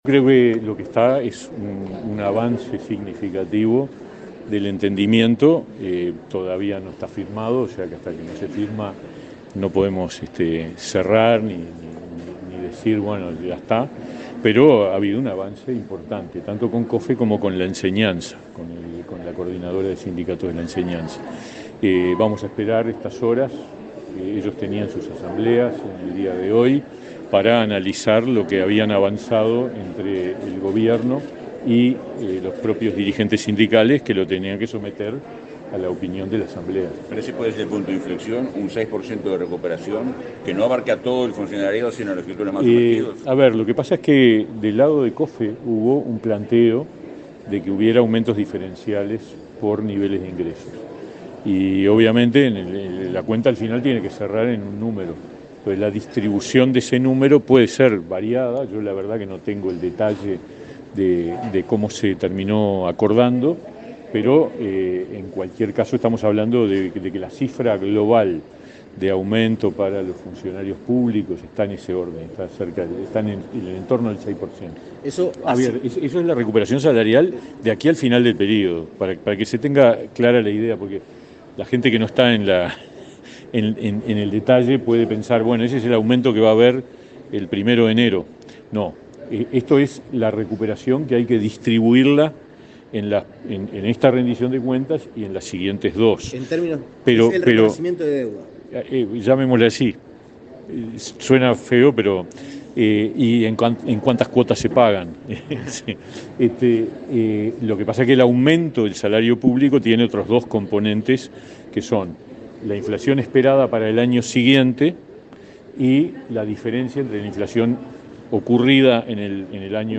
Declaraciones a la prensa del ministro de Trabajo, Pablo Mieres
Al culminar el evento, dialogó con la prensa.